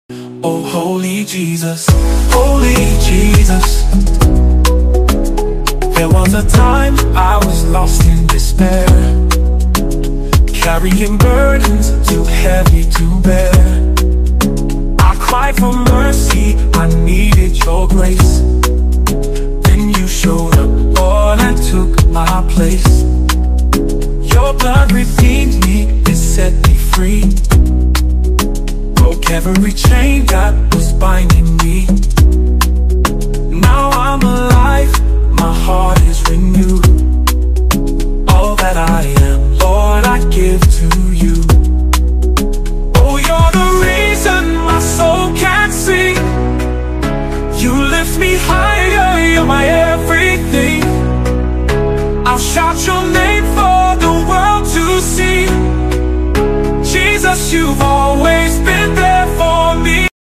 American Gospel Songs
Genre: Gospel/Christian